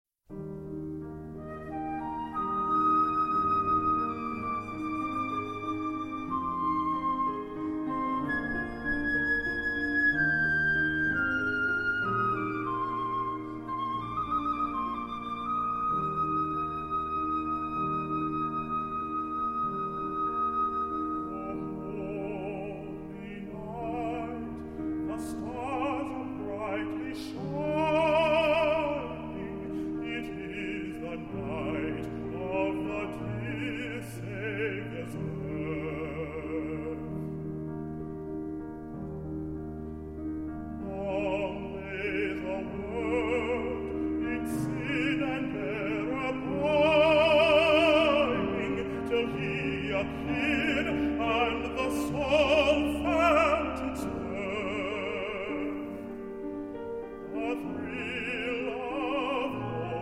Vocal Soloist